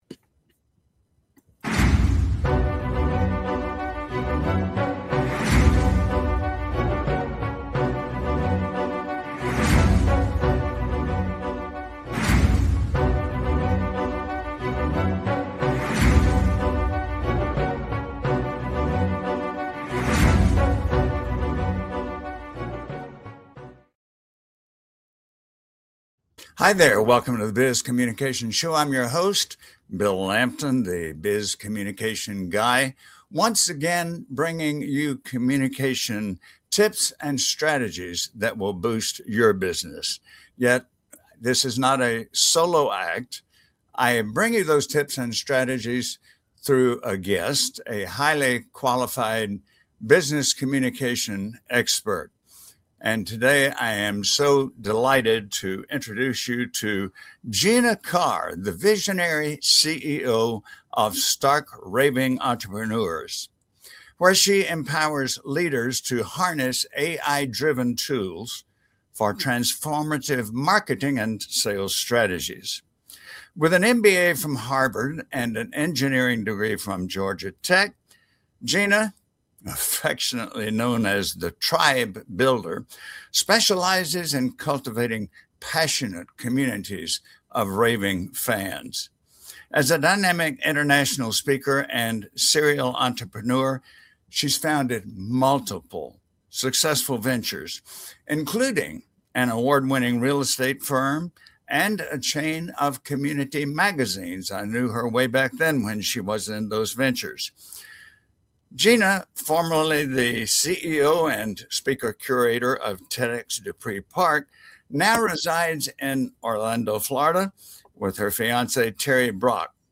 Interview Script